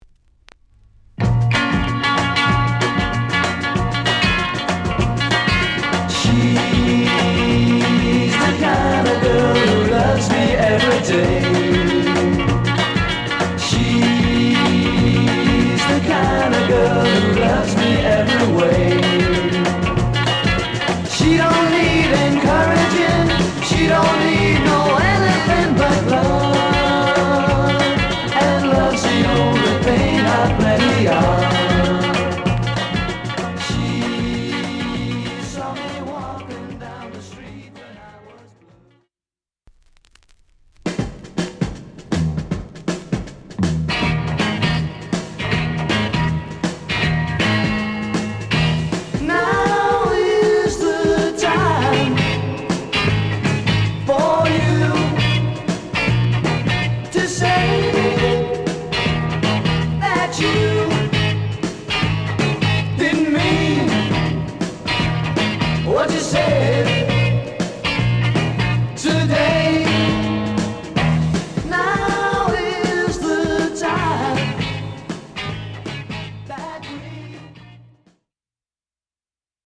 Psych Garage Rock 45.
(light surface marks, light pops, no skips, plays very well)